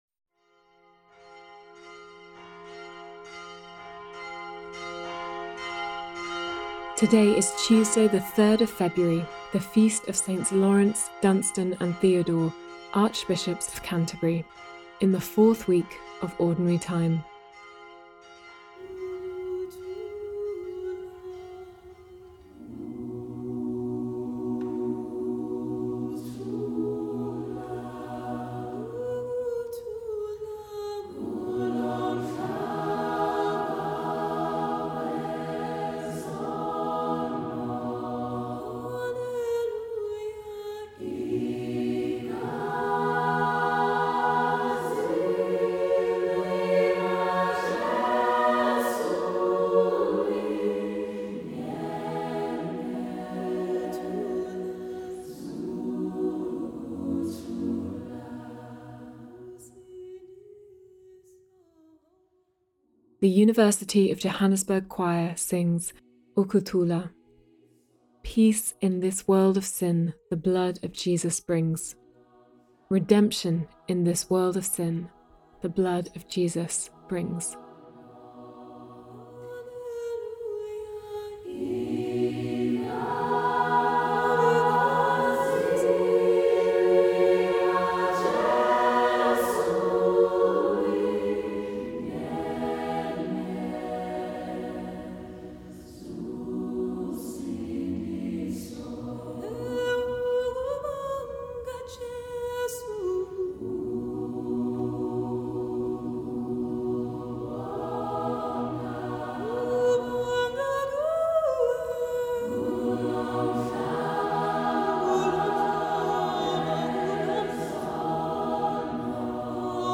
Today’s reading is from the Gospel of Mark. The passage will be read once with pauses on the way for reflection.